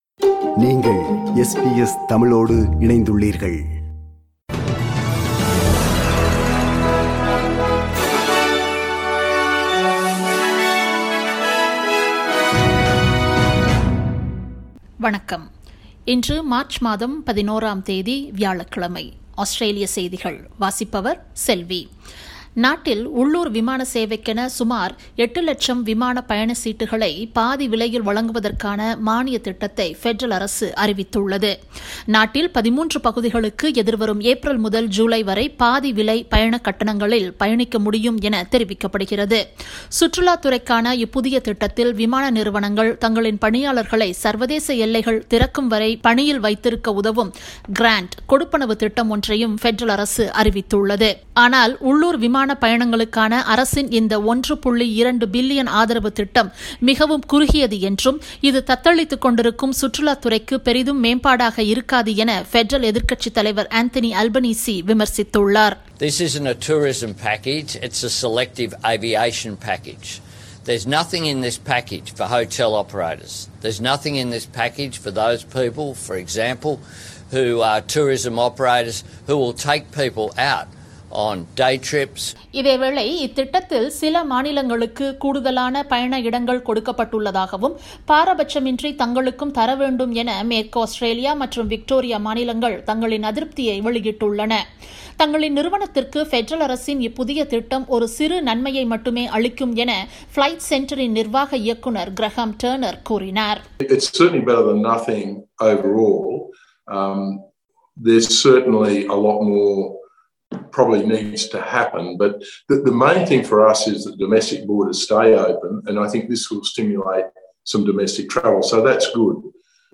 Australian news bulletin for Thursday 11 March 2021.